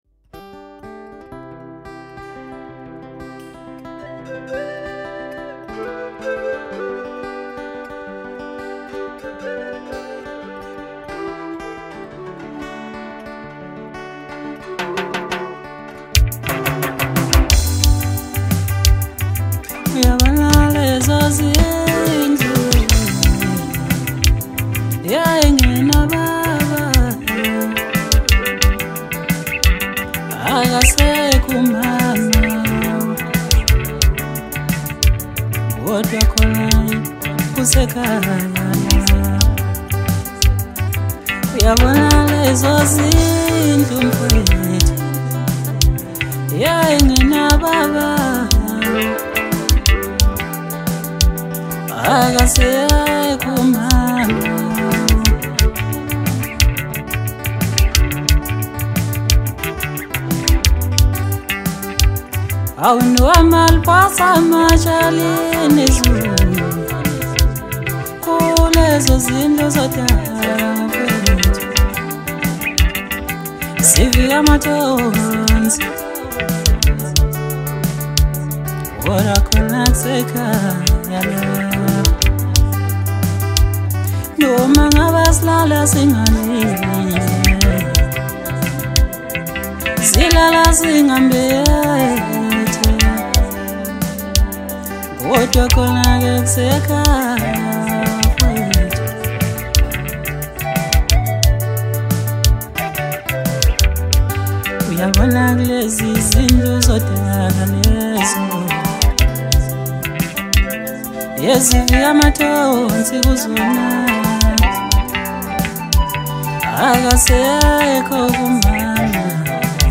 • Genre: Maskandi